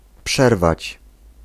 Ääntäminen
IPA: [ˈbryːtˌa]